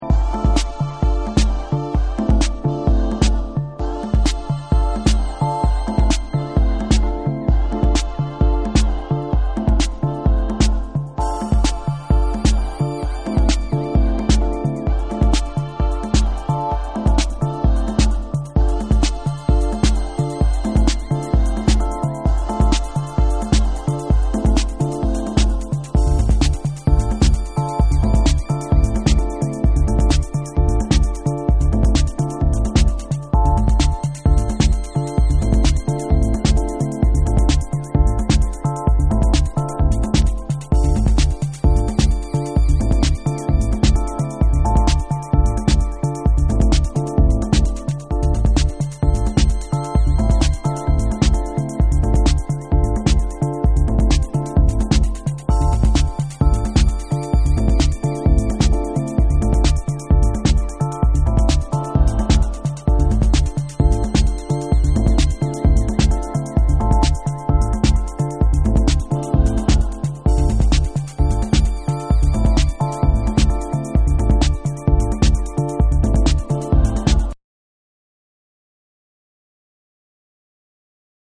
INTELLIGENCE TECHNO